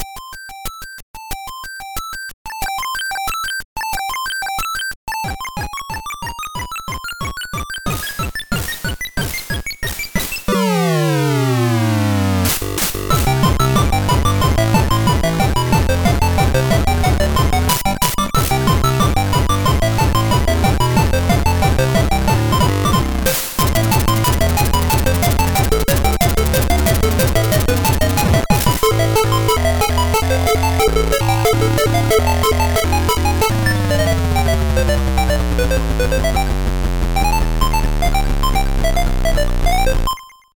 ZX Spectrum + AY
• Звуковой чип AY-3-8912 / YM2149.